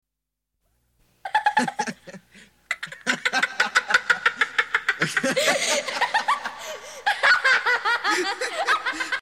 File Name: Laughter.mp3